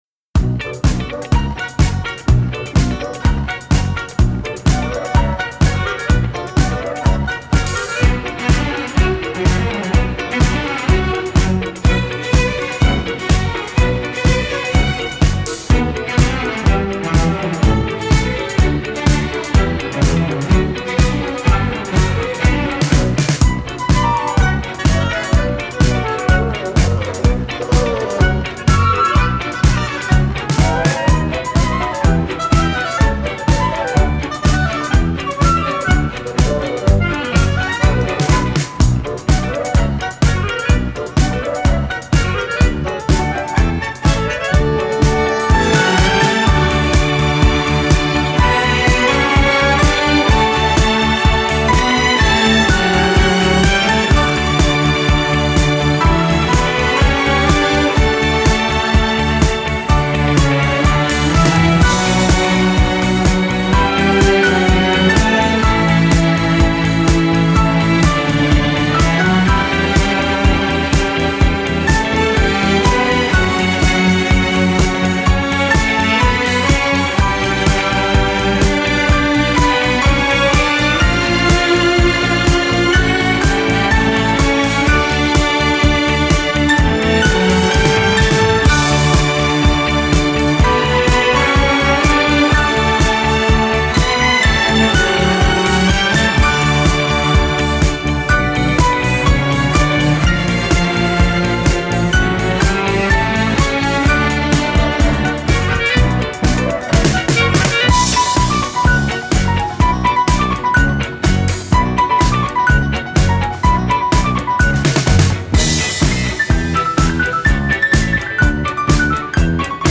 ДИСКО.